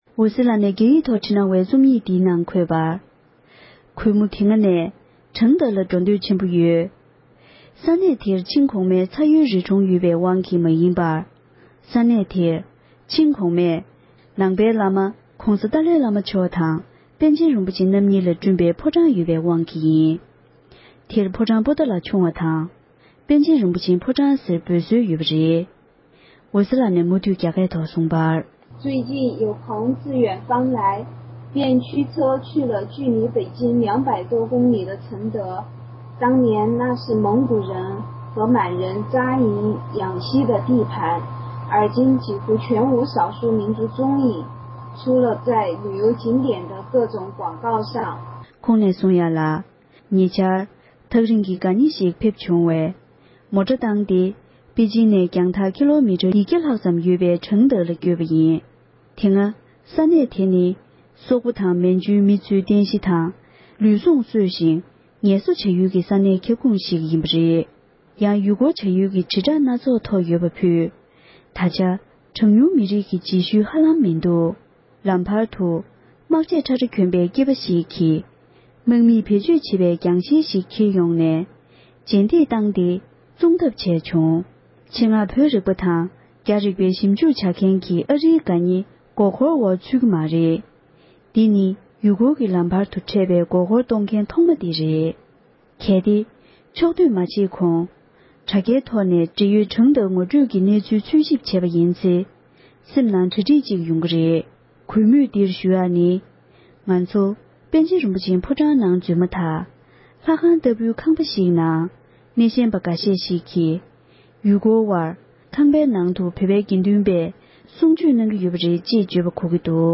ཕབ་བསྒྱུར་དང་སྙན་སྒྲོན་ཞུས་པ་ཞིག་ལ་གསན་རོགས་ཞུ༎